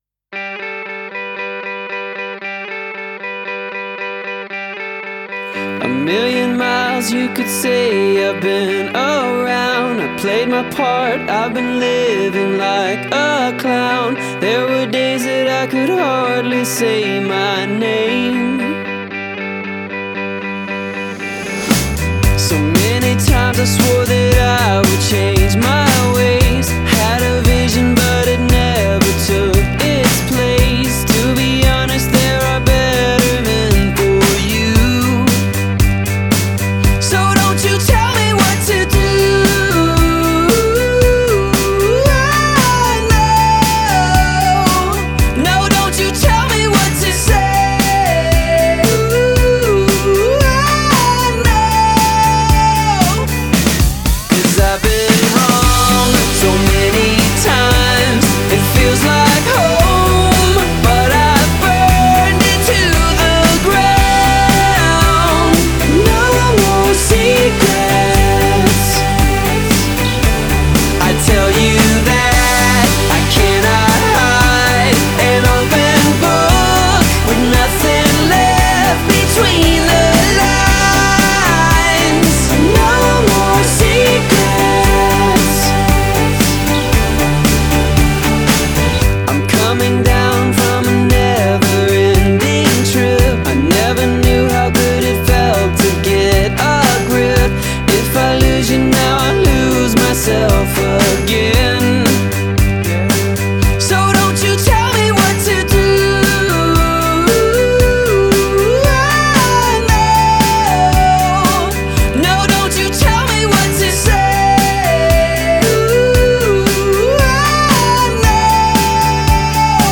Style: Indie Pop